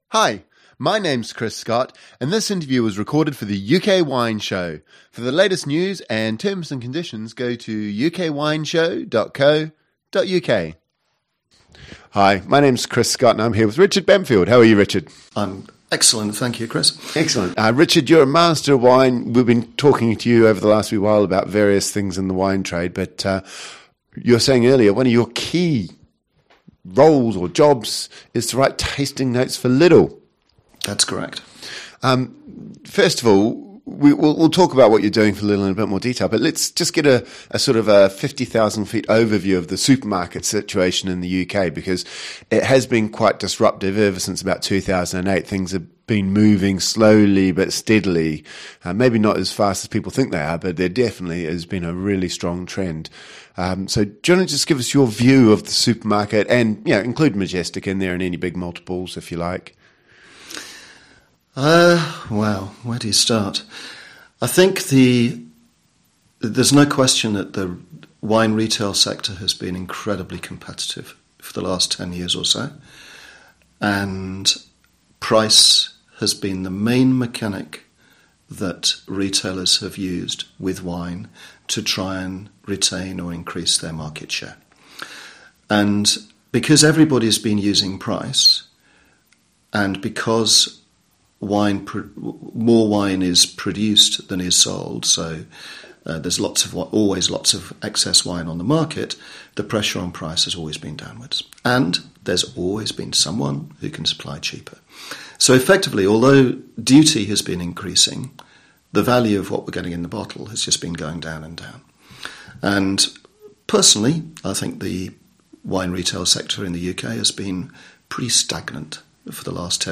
Interview Only